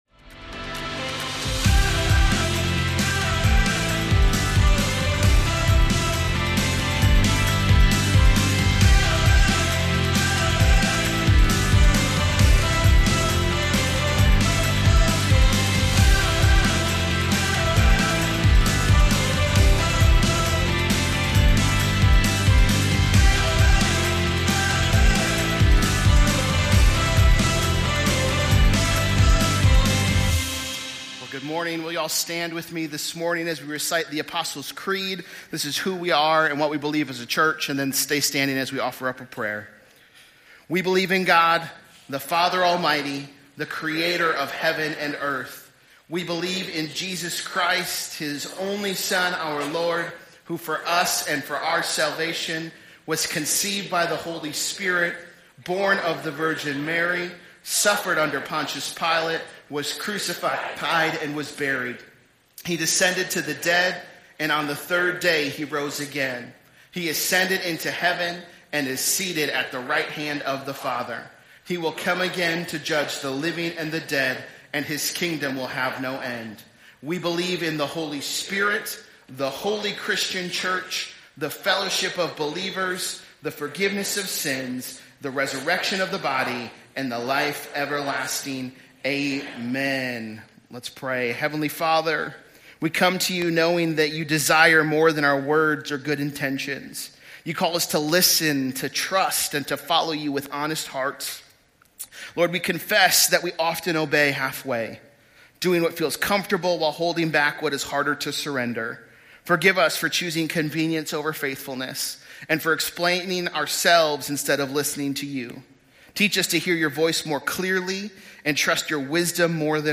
This is the weekly message from Celebration Church in Green Bay, Wisconsin.